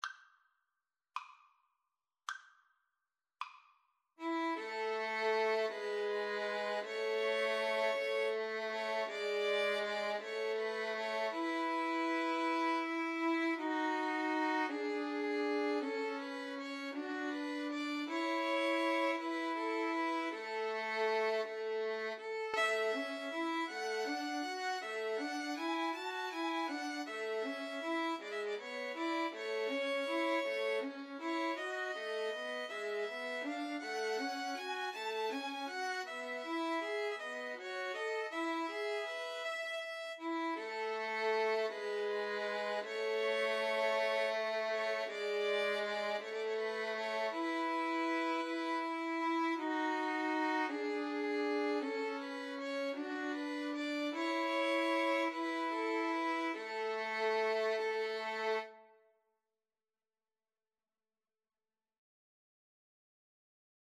6/8 (View more 6/8 Music)
Andante
Pop (View more Pop Violin Trio Music)